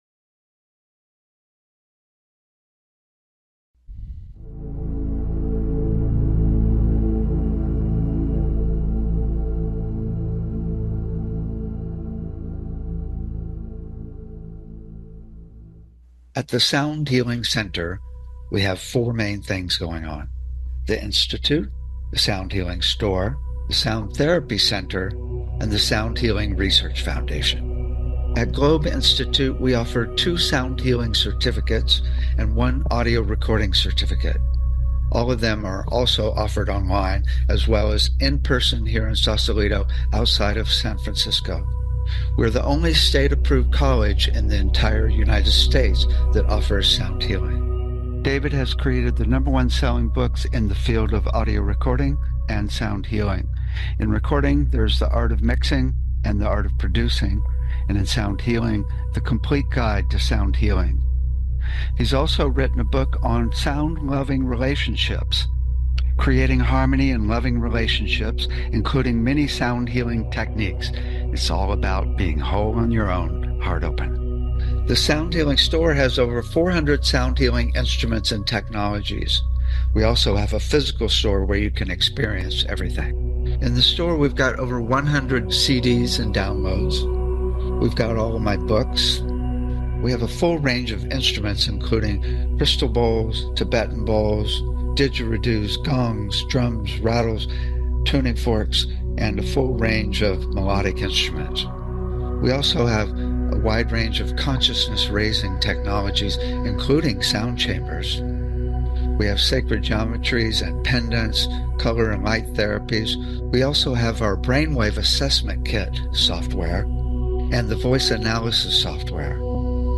Sound Healing Talk Show
The show is a sound combination of discussion and experience